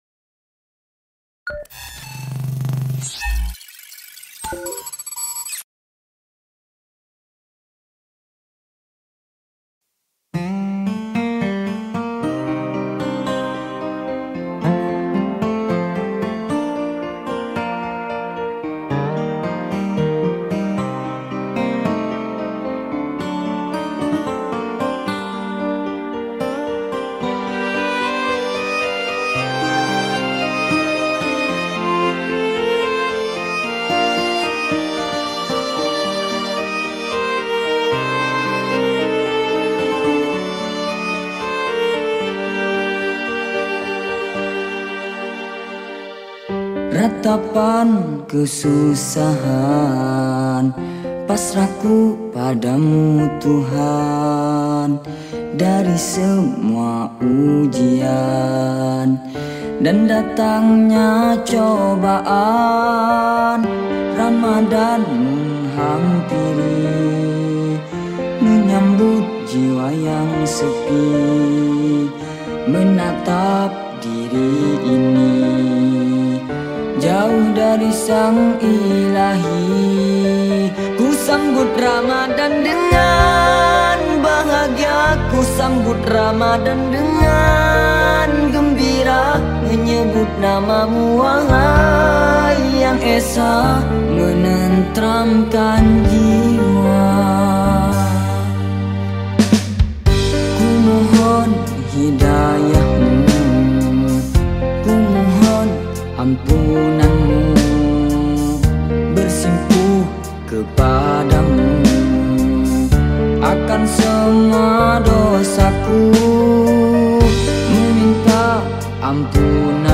Lirik Sholawat